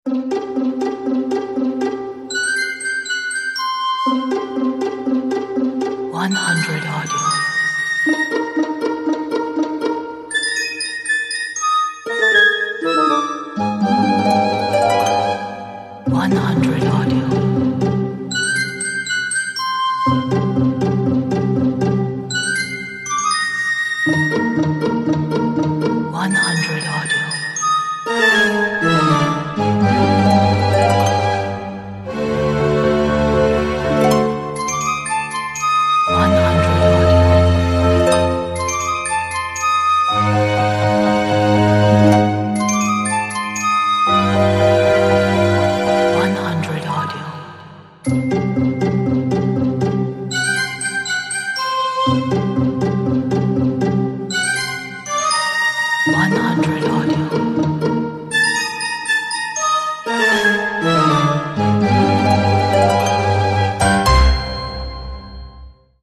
乐趣 可爱 漫画 广告